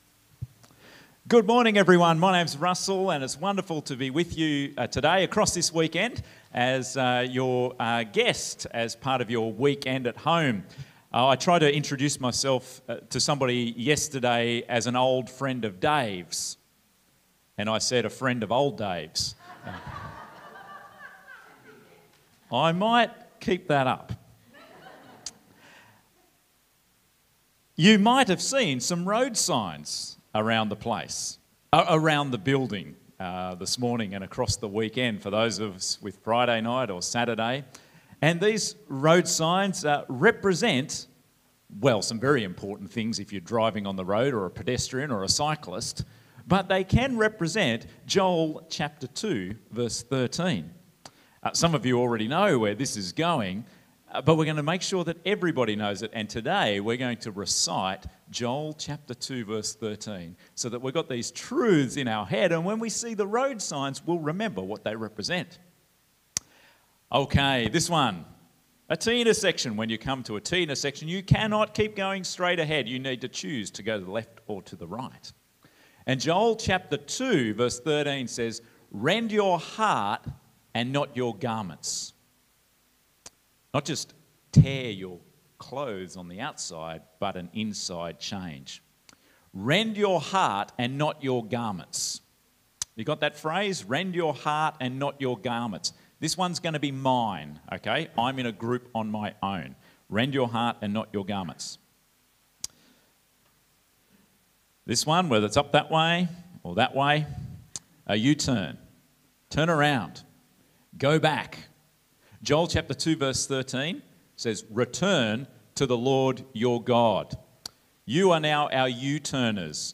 MacChapSermon20Aug23.mp3